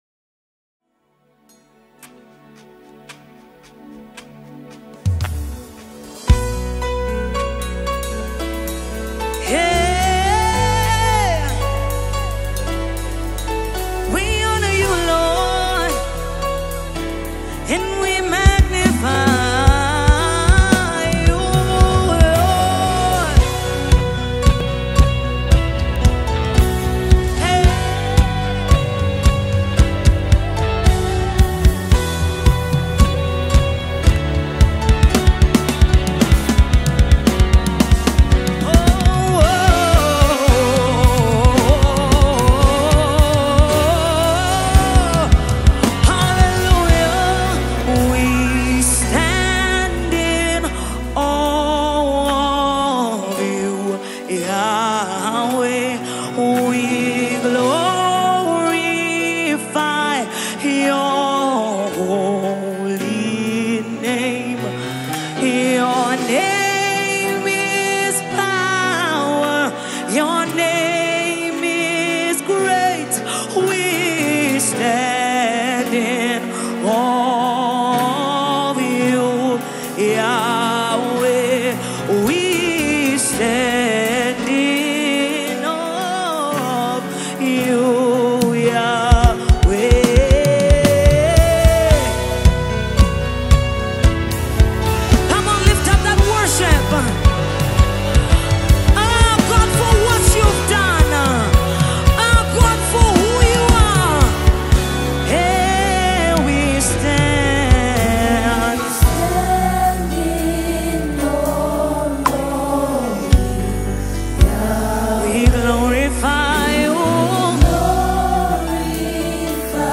The fast-rising gospel music artiste